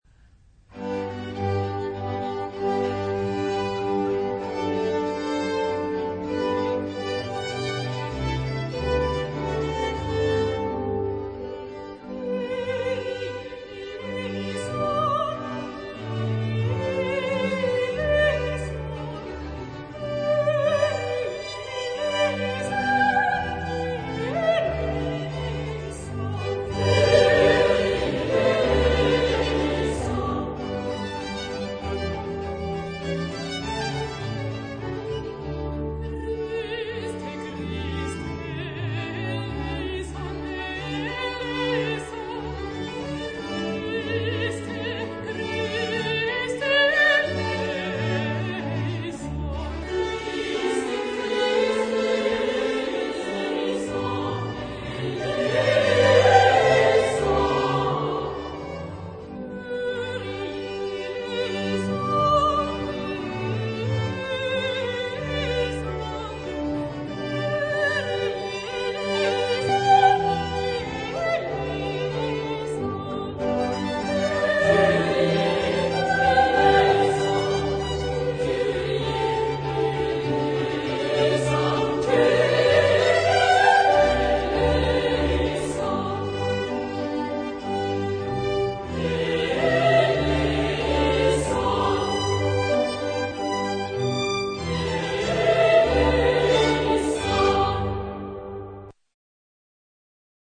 Genre-Style-Form: Sacred ; Classic ; Mass
Type of Choir: SSA  (3 women voices )
Instrumentation: Chamber orchestra  (6 instrumental part(s))
Instruments: Organ (1) ; Strings